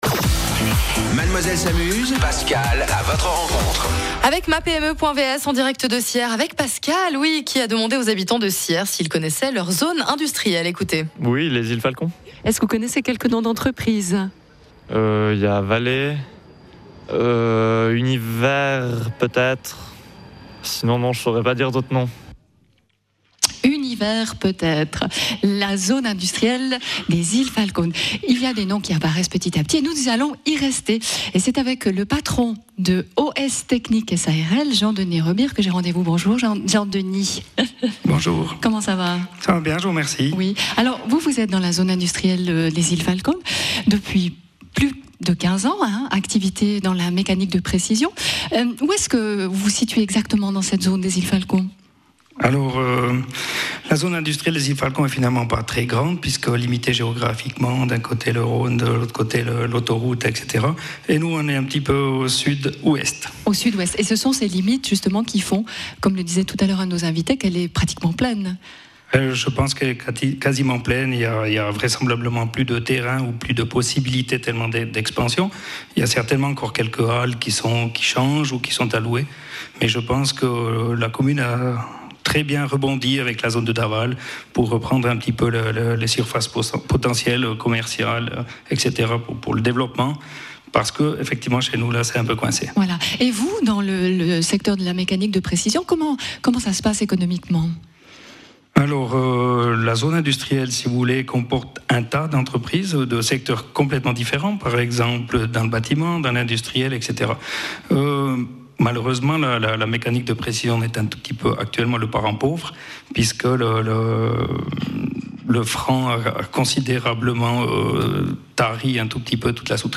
Suite à une présentation du tissu économique par le Président de la ville, les interviews en direct de plusieurs entrepreneurs vous permettront de mieux connaitre une commune et son économie !
Interview de M.